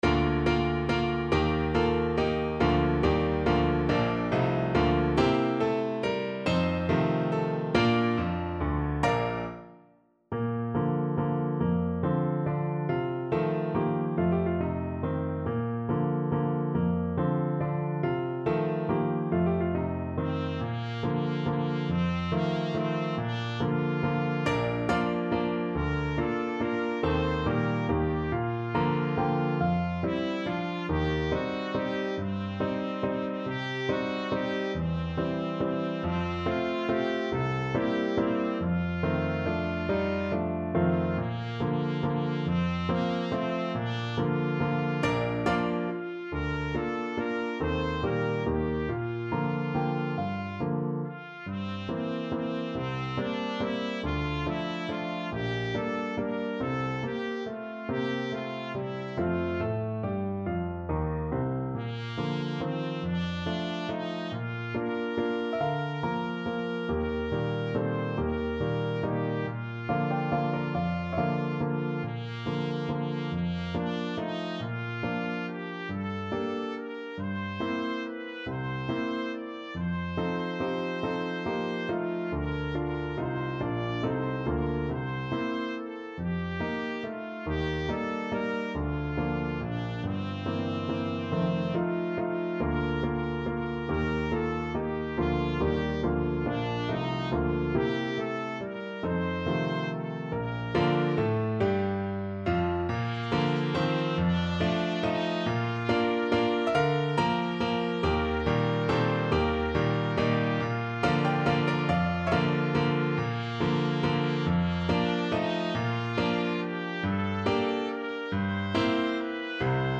TrumpetTrumpet
3/4 (View more 3/4 Music)
~ = 140 Tempo di Valse
Traditional (View more Traditional Trumpet Music)
Rock and pop (View more Rock and pop Trumpet Music)